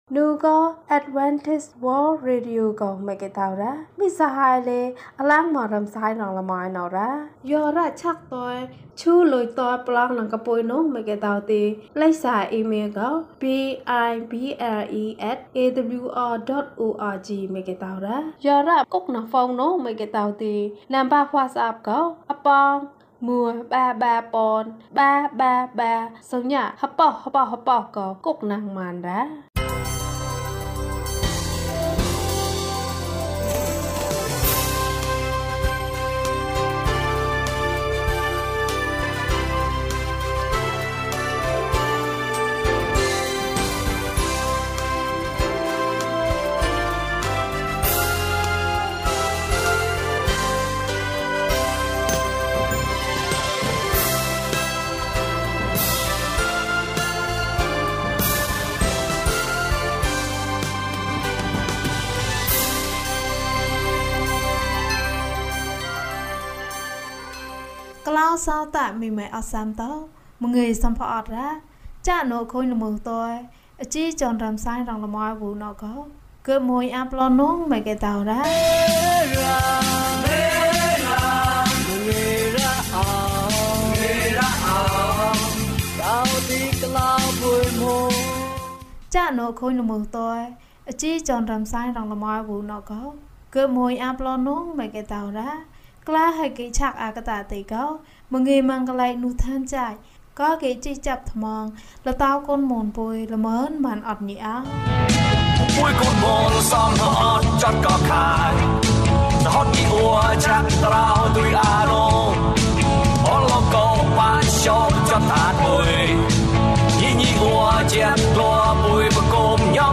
ယုဒနှင့် ဘုရားသခင်။ ကျန်းမာခြင်းအကြောင်းအရာ။ ဓမ္မသီချင်း။ တရားဒေသနာ။